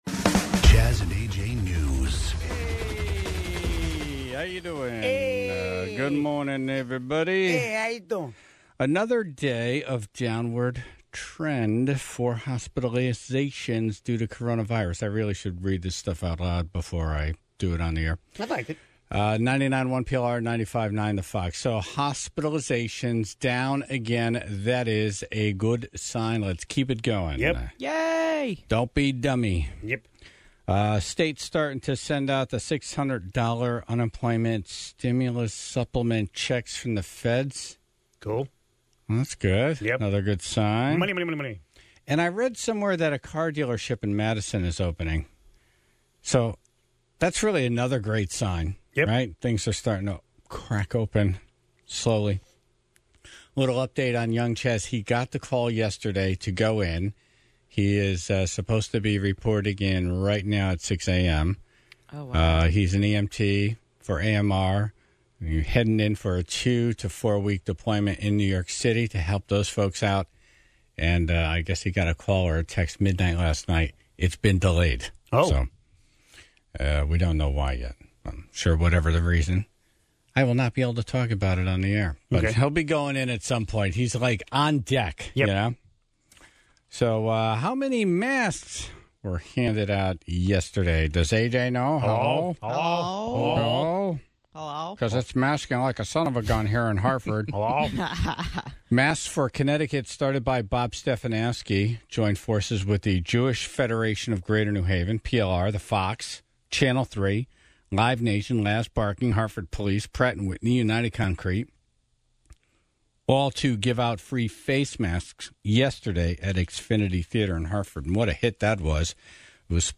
The Tribe called in their suggestions